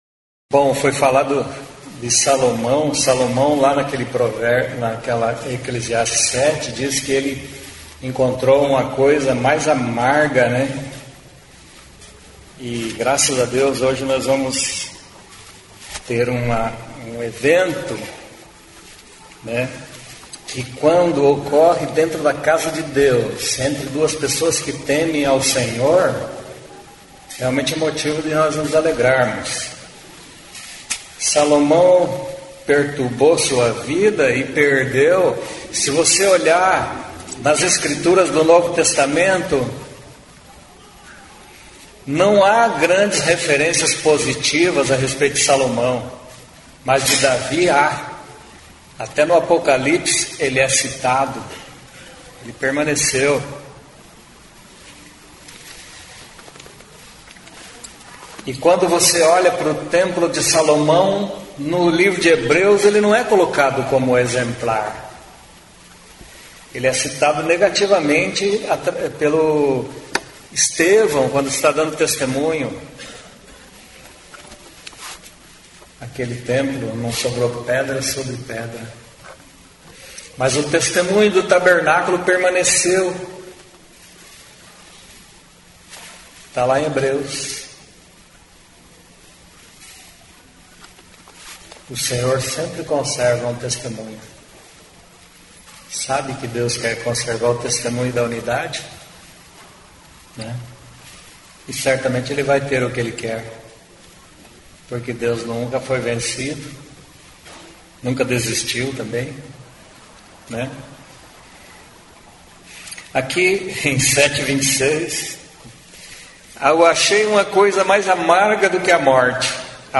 Segunda mensagem
da reunião da igreja em Curitiba no dia 04/02/2023.